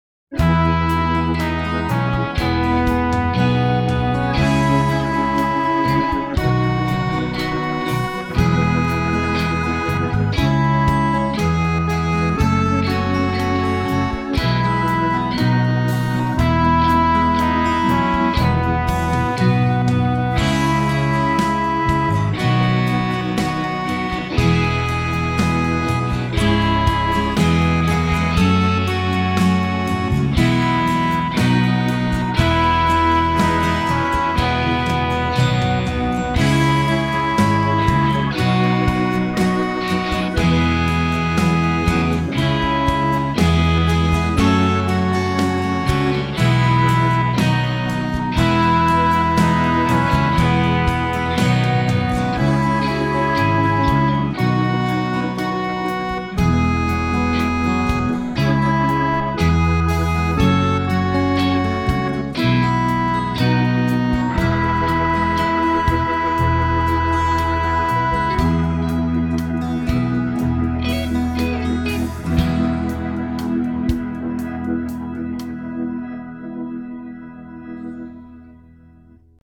This is a South African hymn so naturally you’d want to listen to it in this video from Holland.
The AOV version has three english verses before switching to Zulu. The leader’s part starts at the last note of the unison part and in my backing they are separated to help you learn the piece.